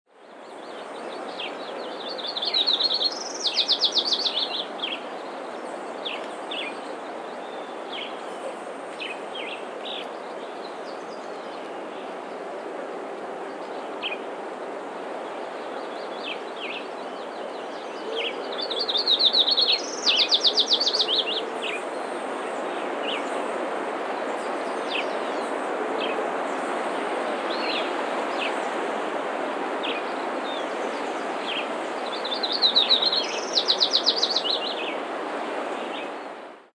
Barbuda Warbler
Voice.  Song consists of three parts: a rich trill, a faster, higher trill, and a series of widely spaced notes:
Barbuda’s song resembles that of Adelaide’s in vocal quality but has more complex phrasing.